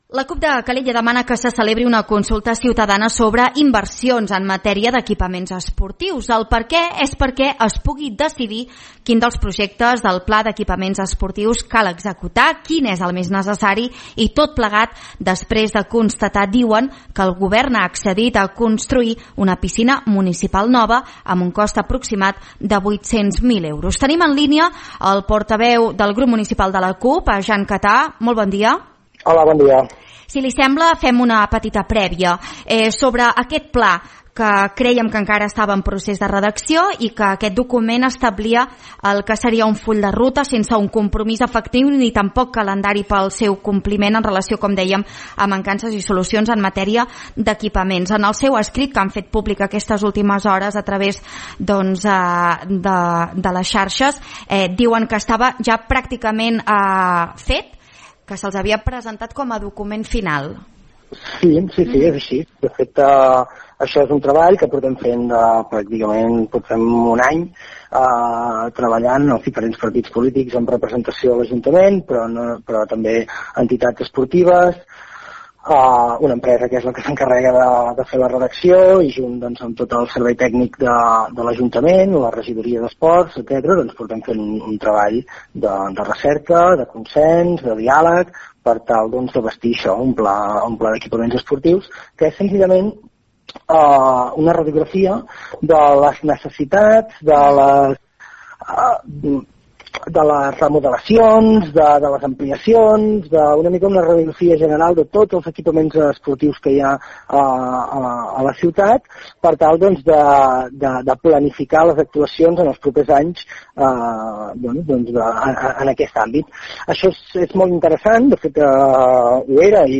El regidor portaveu de la CUP, Jan Catà, ha explicat en una entrevista a l’Info Migdia que la decisió de l’executiu esberla el rigor de la feina feta fins ara.
1989-ENTREVISTA-PISCINA-CUP-JAN-CATÀ.mp3